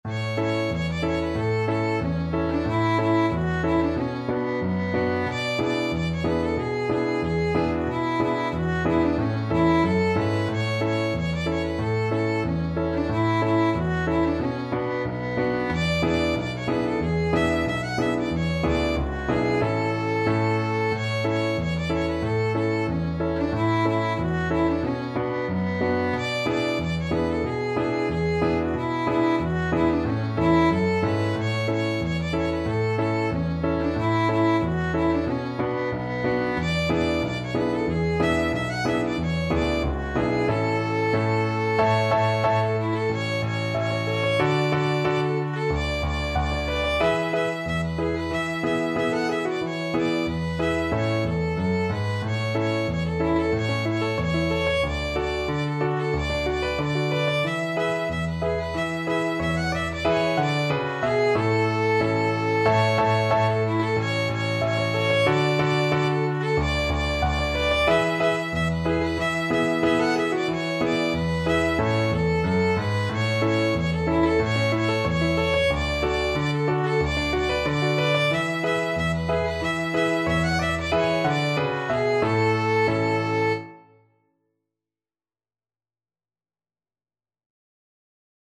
World Trad. Alfred Pa Hultet (Polka) Violin version
Violin
Traditional Music of unknown author.
2/4 (View more 2/4 Music)
Moderato =c.92
A major (Sounding Pitch) (View more A major Music for Violin )